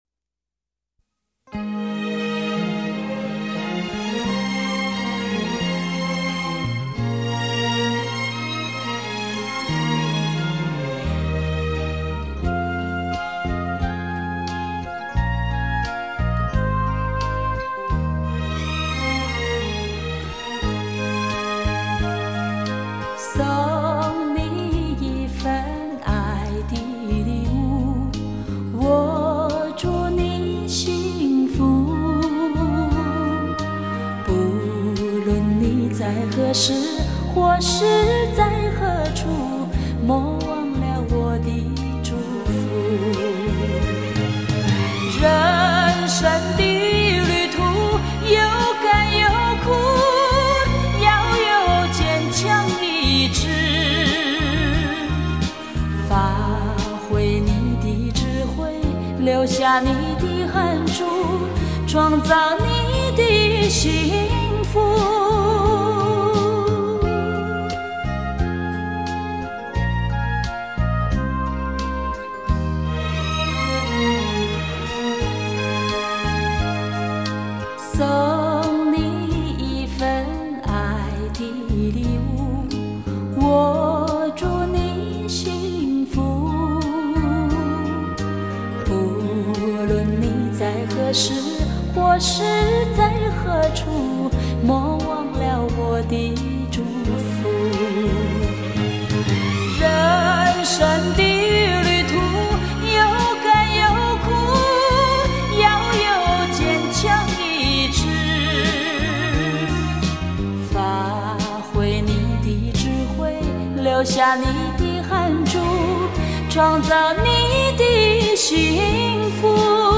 歌艺唱功较之刚出道的时候更为成熟，对于感情的把握也更加丝丝入扣
特别是这些歌曲经过重新配器，既保留原曲的韵味，又体现了当时的最高质量录音，即便在今日听来依旧绕梁三日、娓娓动听。